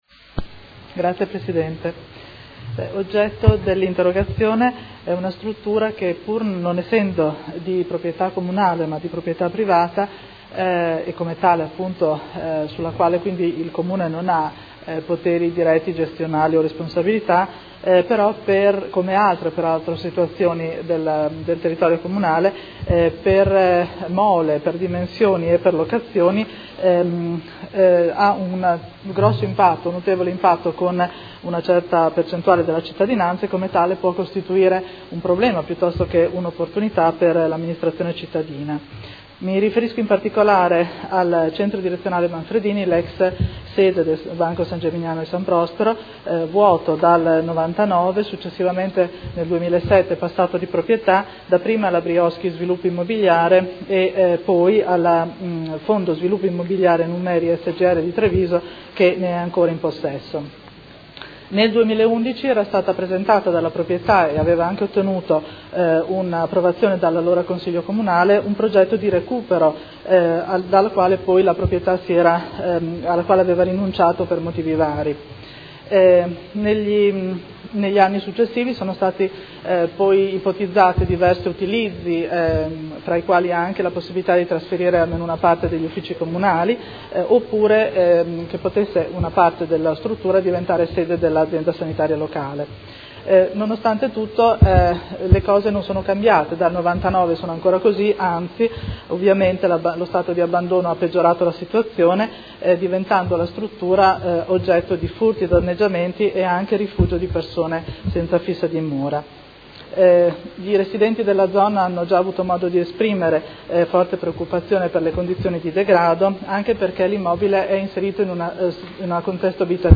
Chiara Pacchioni — Sito Audio Consiglio Comunale
Interrogazione della Consigliera Pacchioni (P.D.) avente per oggetto: Centro Direzionale Manfredini